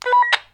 radio_beep.ogg